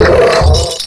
Cri de Noacier dans Pokémon Noir et Blanc.